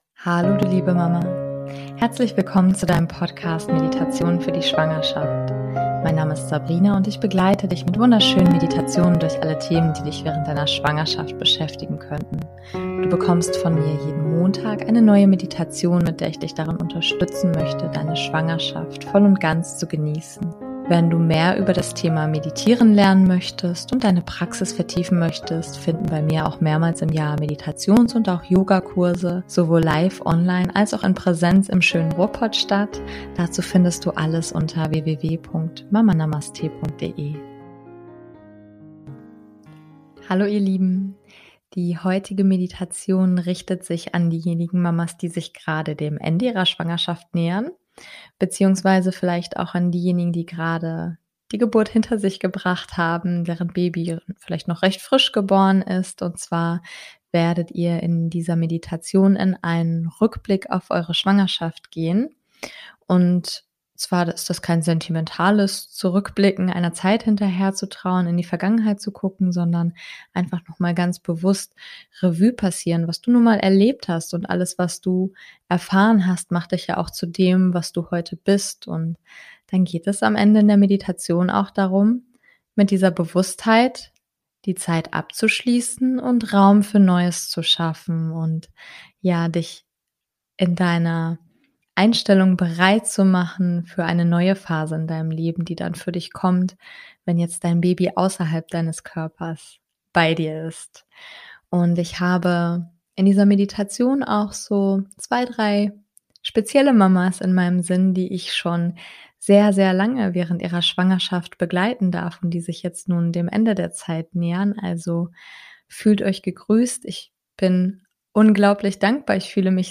#051 - Meditation Rückblick auf die Schwangerschaft und bereit machen für Neues ~ Meditationen für die Schwangerschaft und Geburt - mama.namaste Podcast